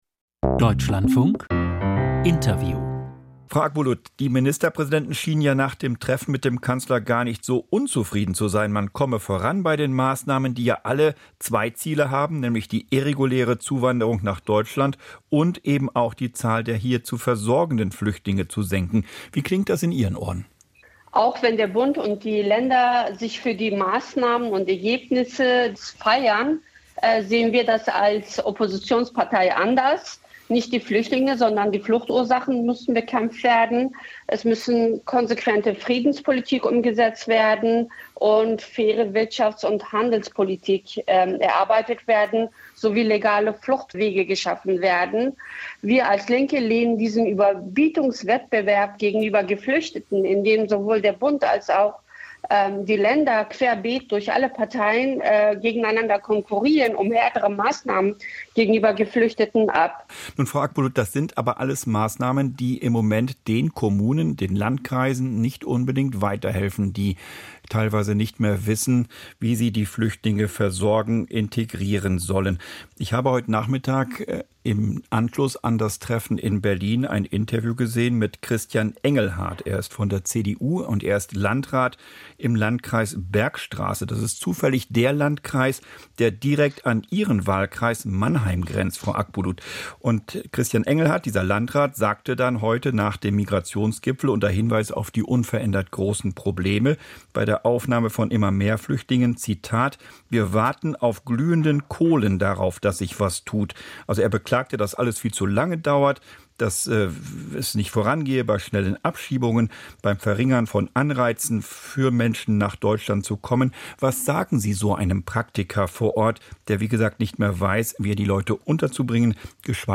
Ministerpräsidentenkonferenz zu Migration/ Interview Gökay Akbulut, Die Linke